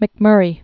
(mĭk-mûrē, -mŭrē)